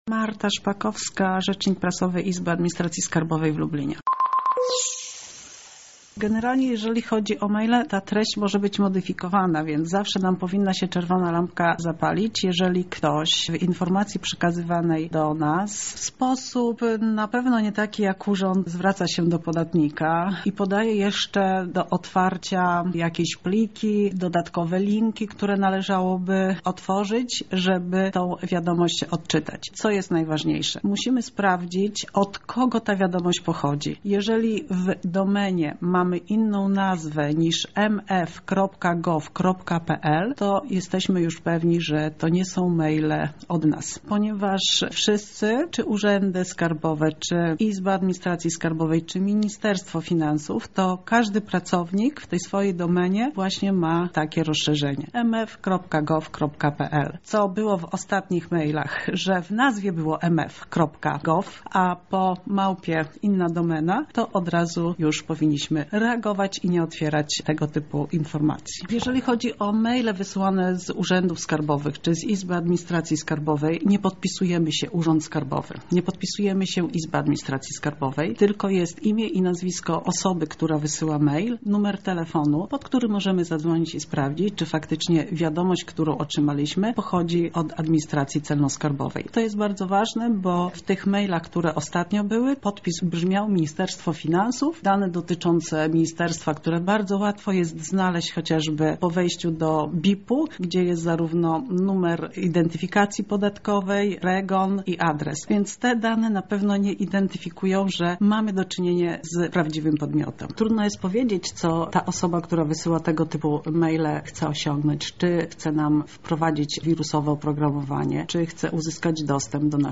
Więcej na ten temat usłyszycie w rozmowie z ekspertem: